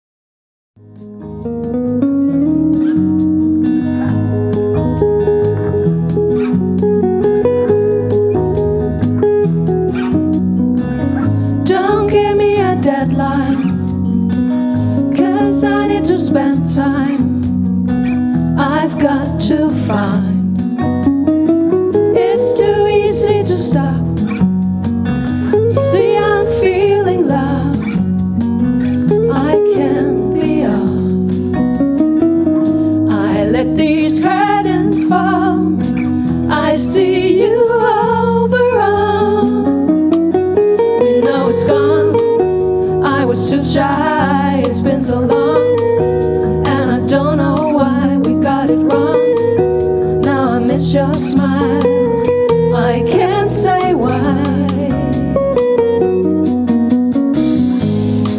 Americana Folk Indie Singer/songwriter
Nordic Americana musik
banjo, guitar, slide og bas